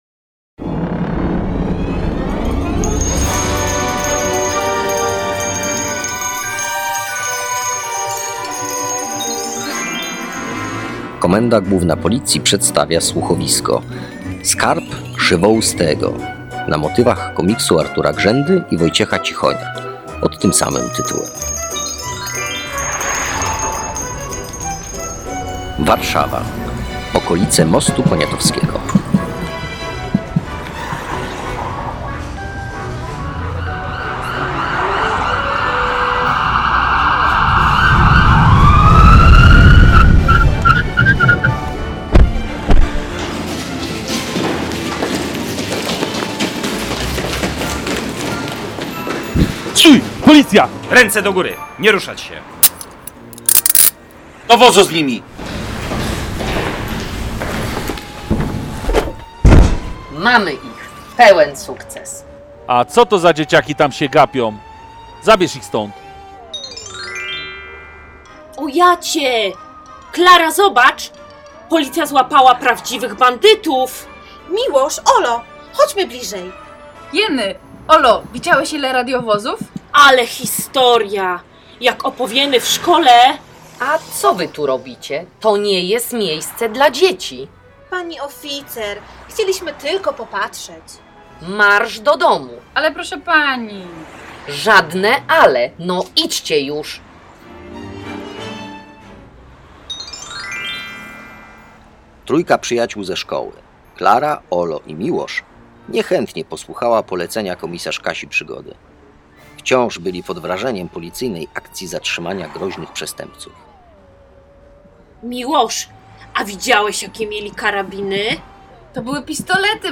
Słuchowisko dla dzieci Skarb Krzywoustego